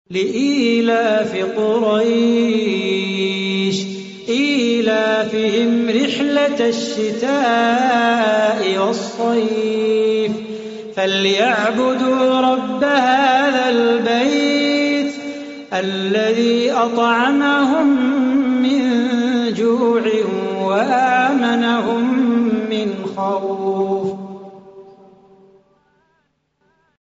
صلاح بو خاطر قارئ إمارتي، ورئيس مجلس إدارة مؤسسة القرآن الكريم والسنة بالشارقة.